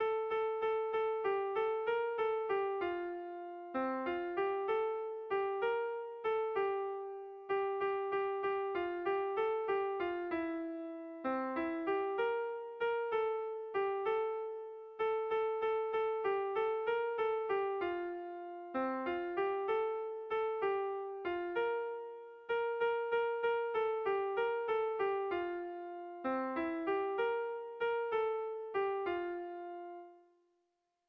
Erlijiozkoa
Zortziko handia (hg) / Lau puntuko handia (ip)
ABAD